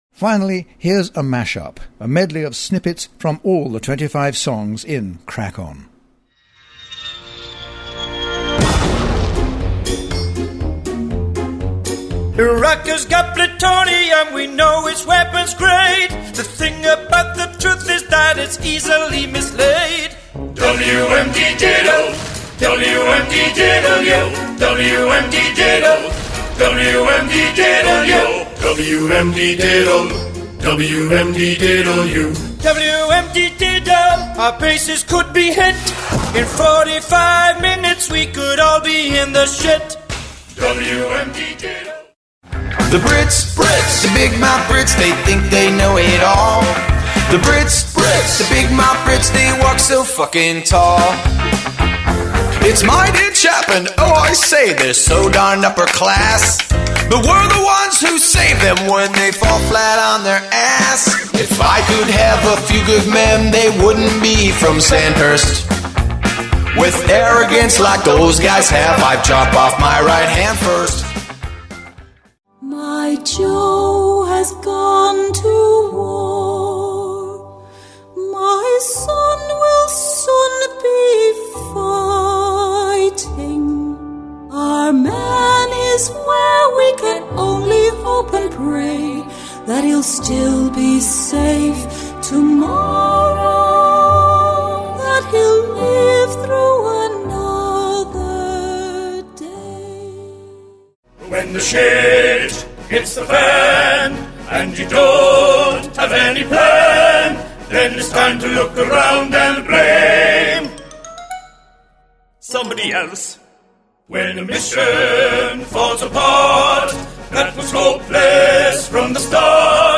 Listen to a 10-minute mashup of the show's songs here .
50 MASHUP of All Songs.mp3